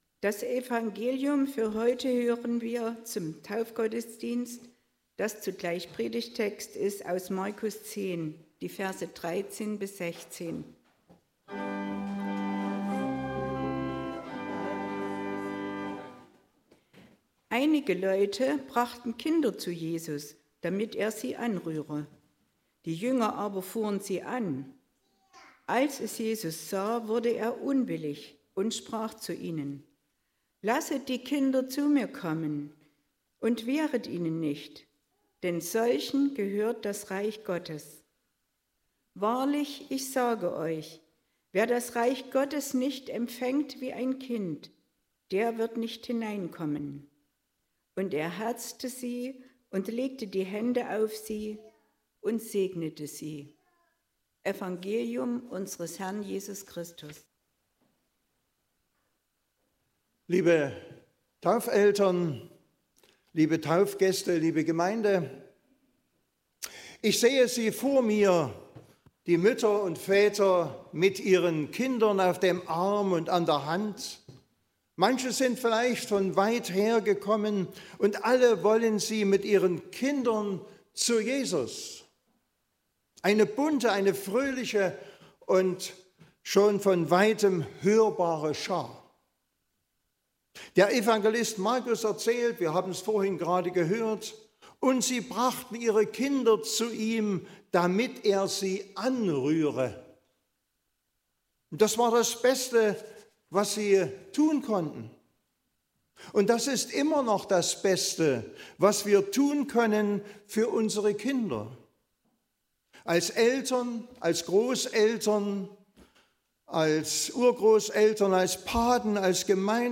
13-16 Gottesdienstart: Taufgottesdienst Wir können nichts Besseres tun